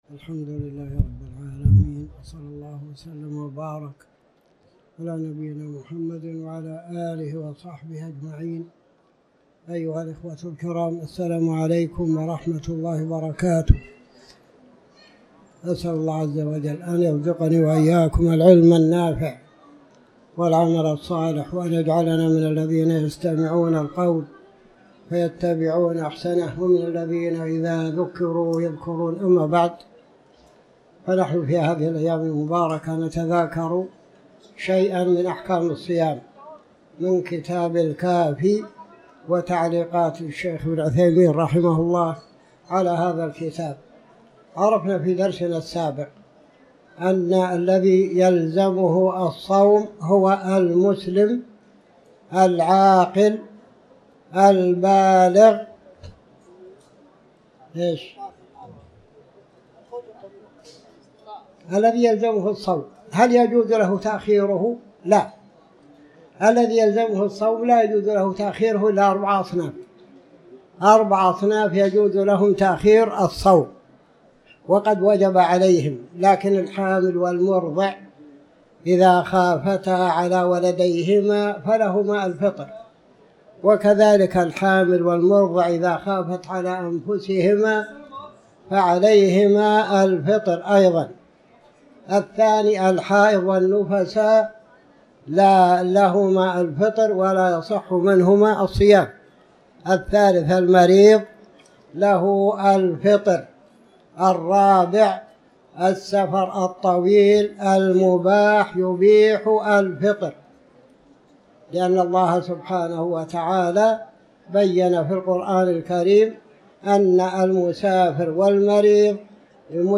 تاريخ النشر ١ رمضان ١٤٤٠ هـ المكان: المسجد الحرام الشيخ